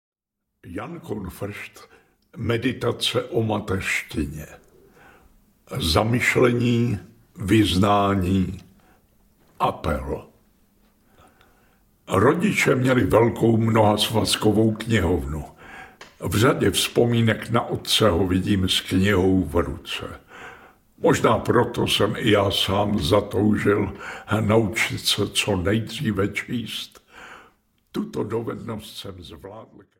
Ukázka z knihy
• InterpretAlfred Strejček